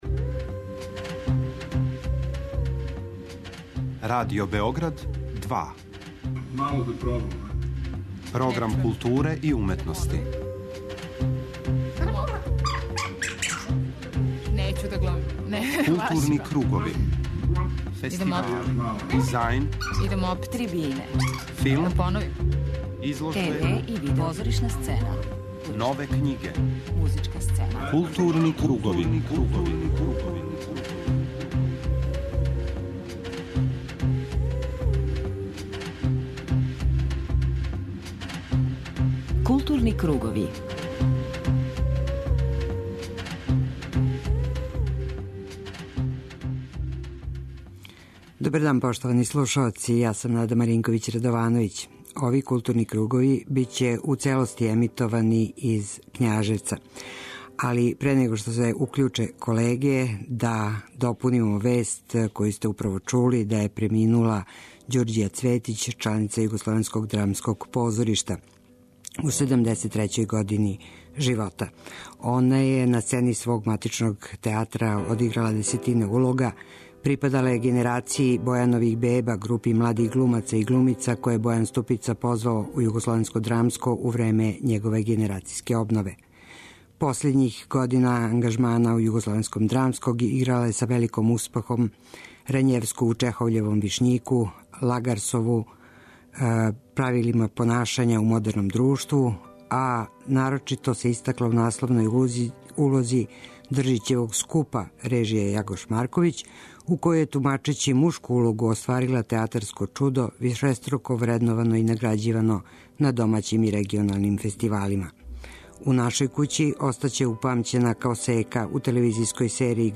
Емисију 'Културни кругови' данас реализујемо уживо из Књажевца, где је у току 54. Фестивал културе младих Србије.